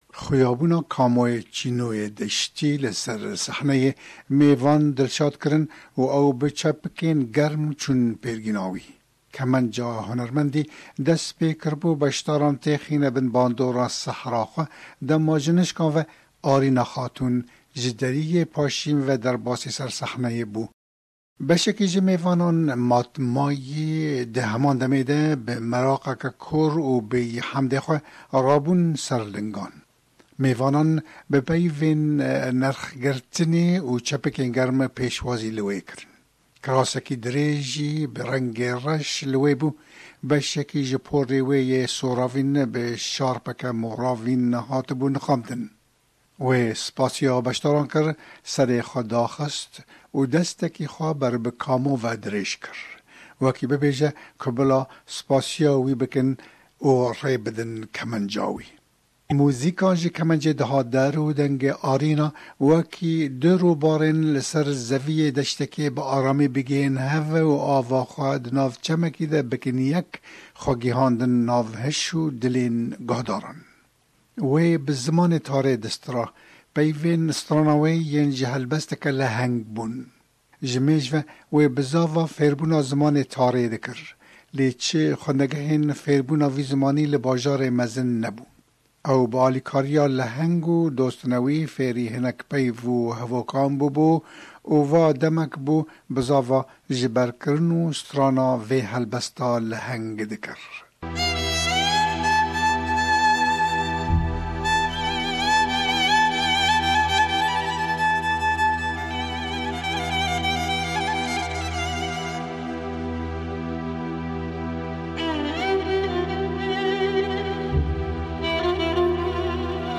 Xwendineke ji naveroka romana Shahînê Bekirê Soreklî VEGER